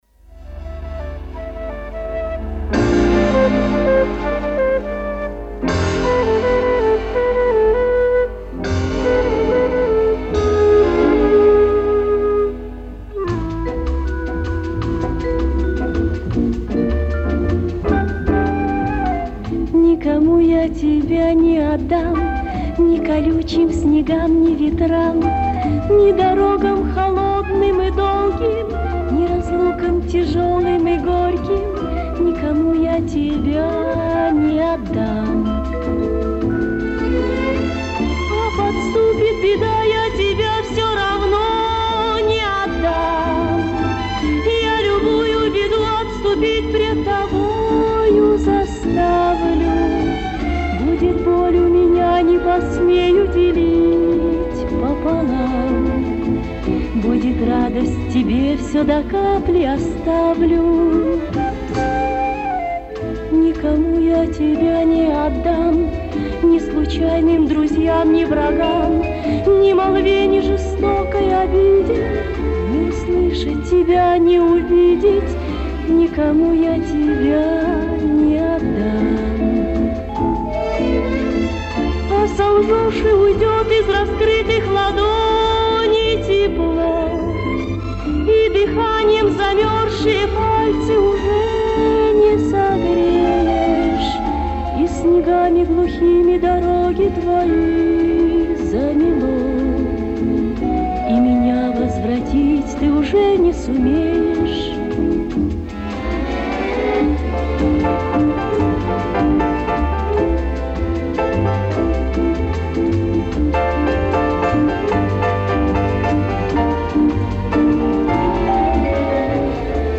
Записи эфирные с радио